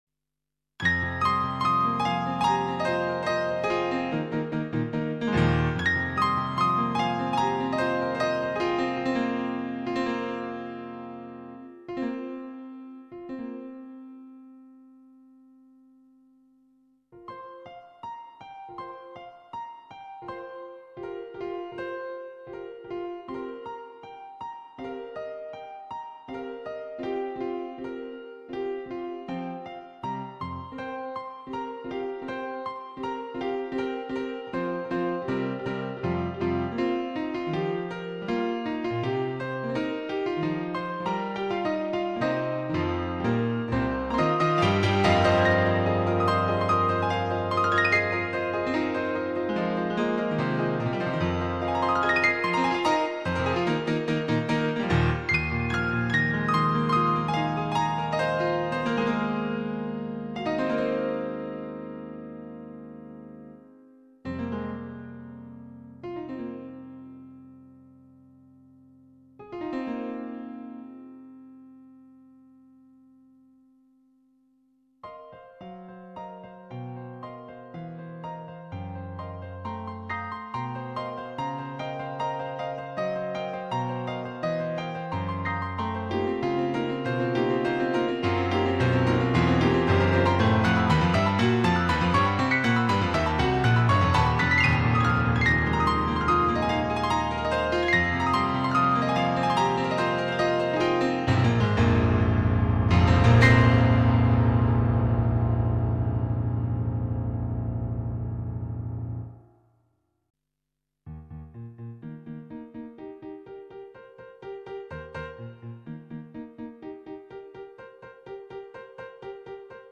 nhạc độc tấu
cho đàn piano độc tấu